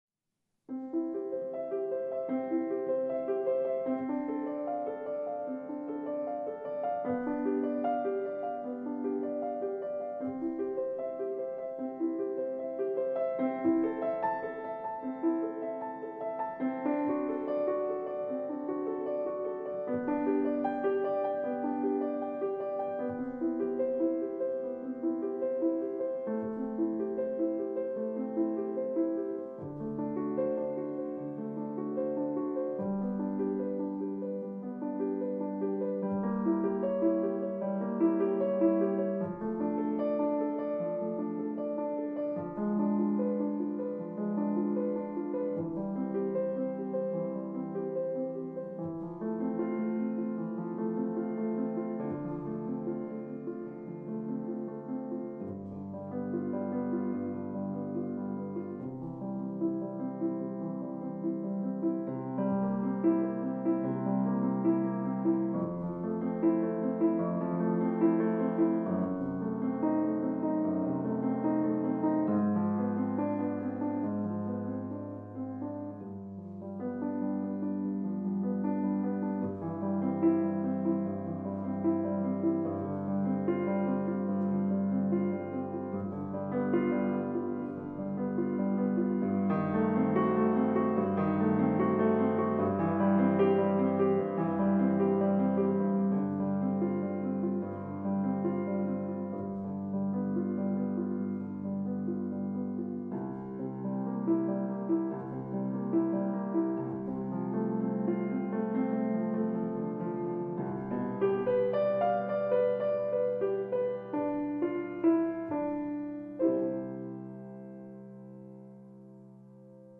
Жанр: Classical Издание